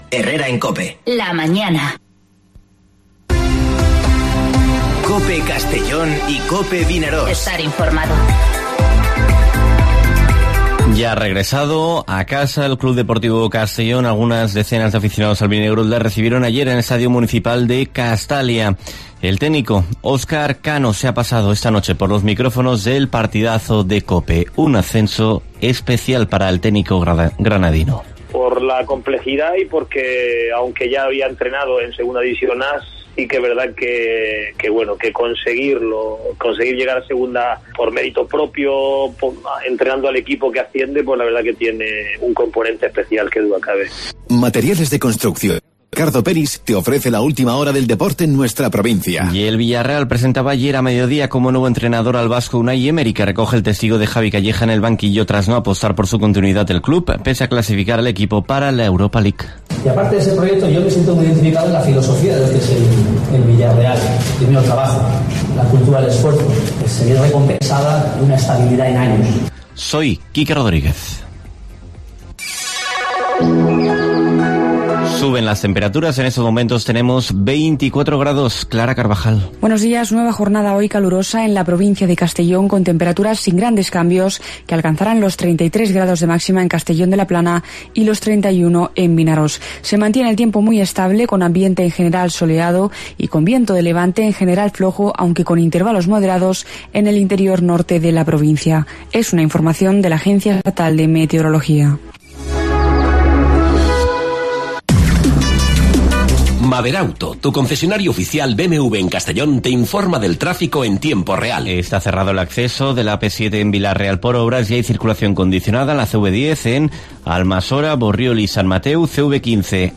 Informativo Herrera en COPE en la provincia de Castellón (28/07/2020)